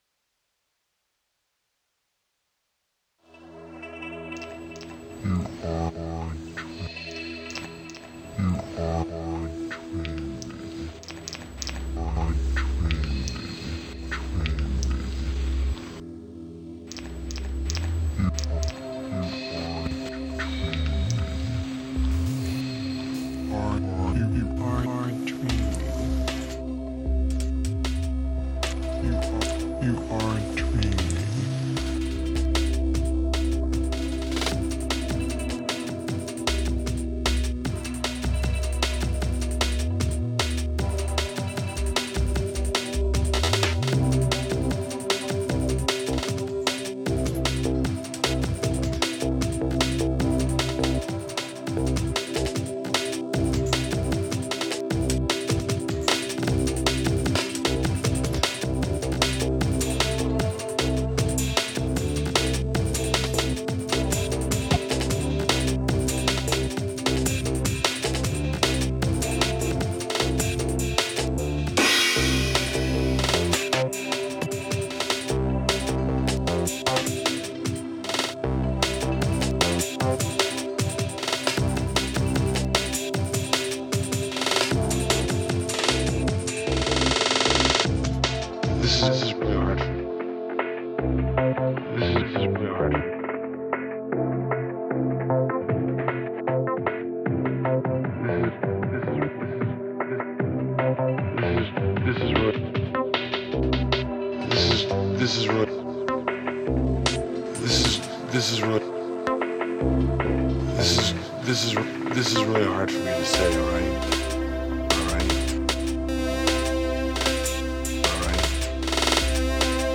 Index of /music/tracks/breakbeat